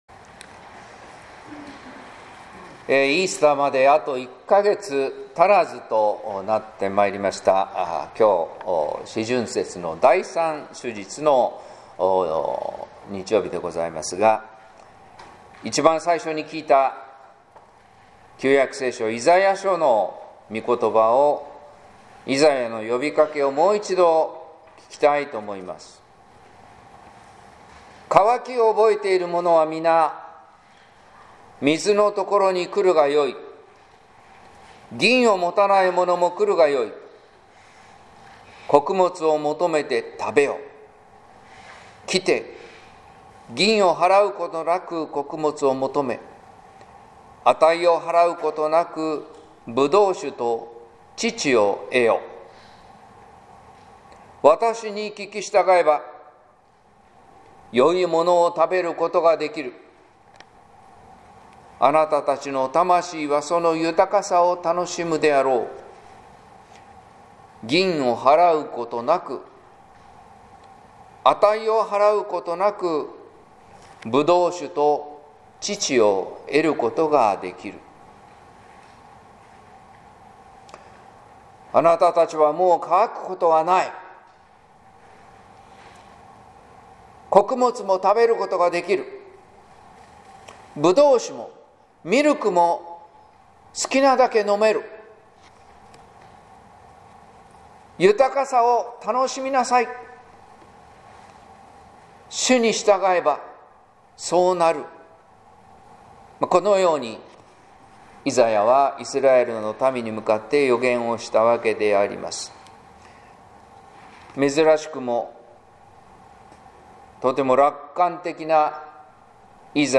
説教「主に立ち帰れ」（音声版） | 日本福音ルーテル市ヶ谷教会